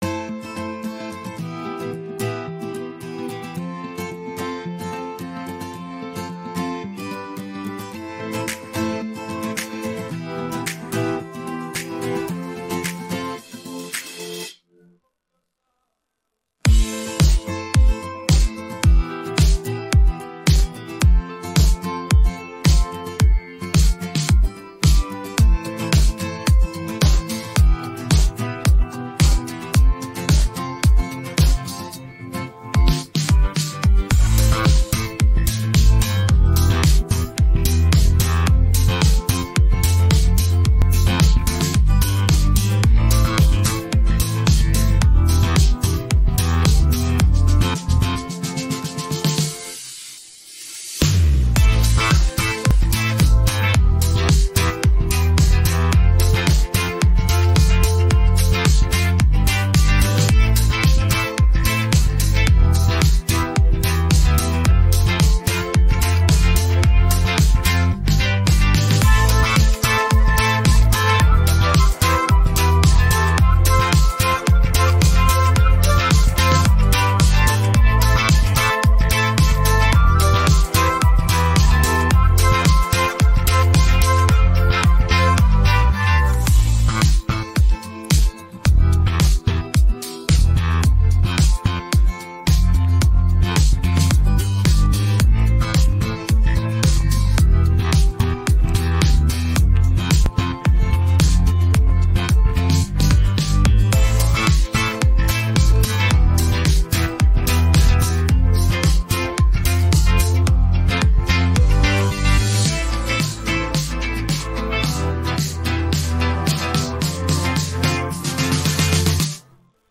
rock караоке 39